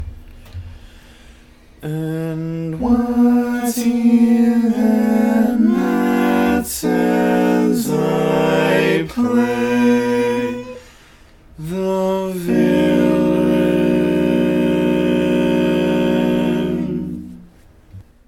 Key written in: E Major
How many parts: 4
Type: Barbershop
All Parts mix:
Learning tracks sung by